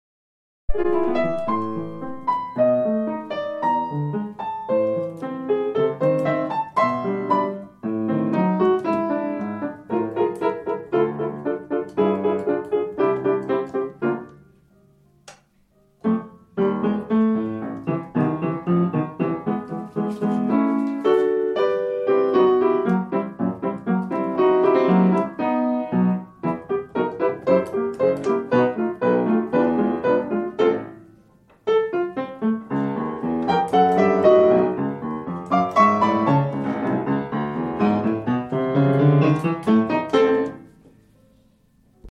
Dring 3rd Movement 2nd Half Piano Only